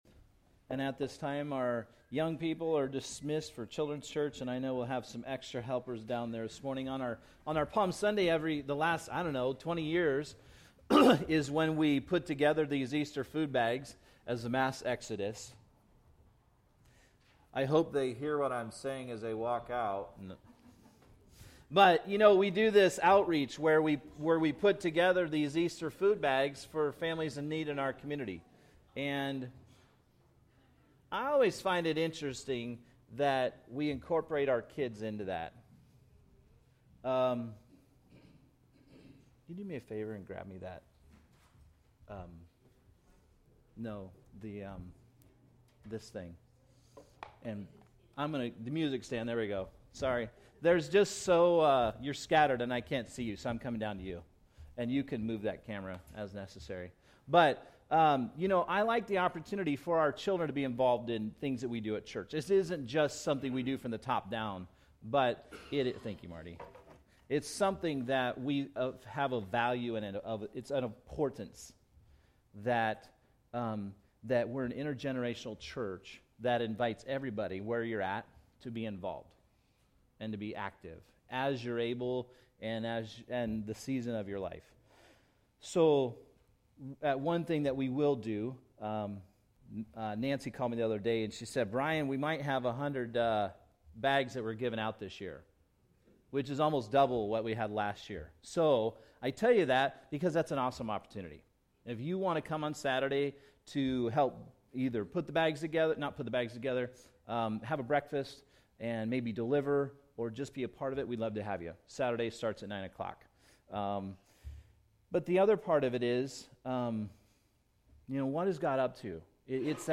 Sunday Worship from Skyline Family Fellowship on Vimeo.